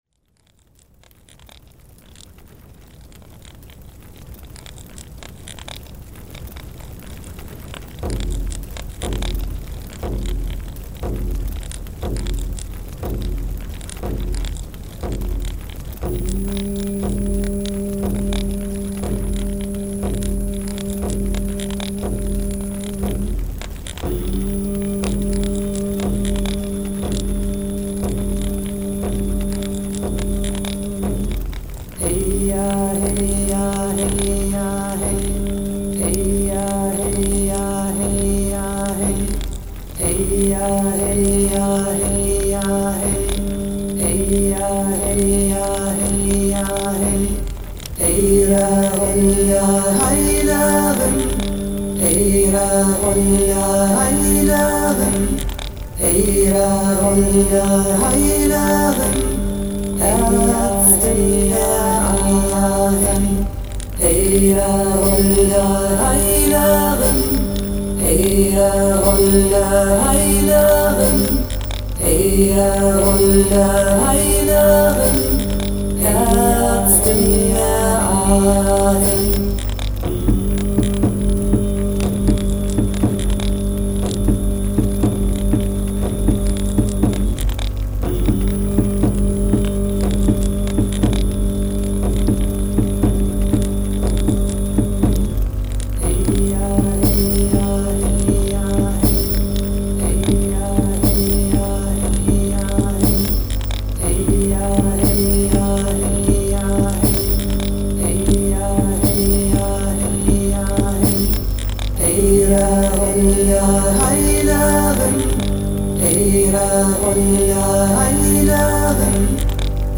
Eir_Chant.mp3